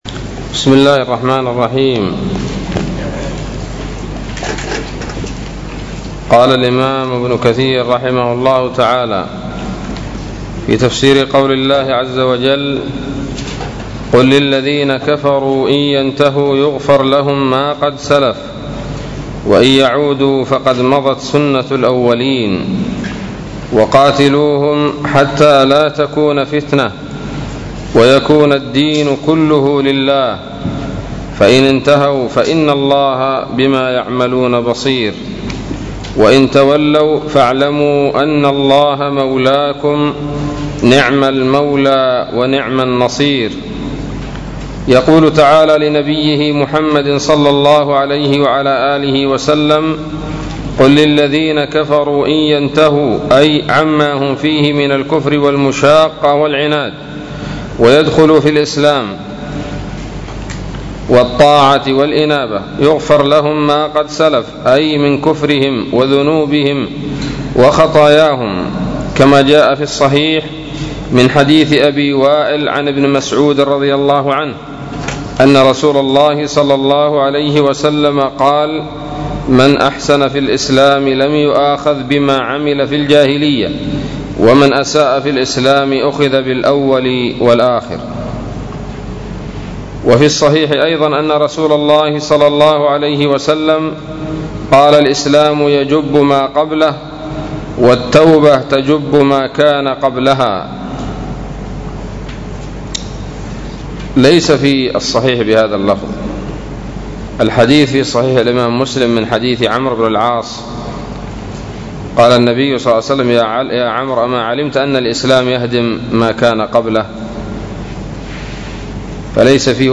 الدرس الحادي والعشرون من سورة الأنفال من تفسير ابن كثير رحمه الله تعالى